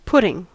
Ääntäminen
US
IPA : /ˈpʰʊtɪŋ/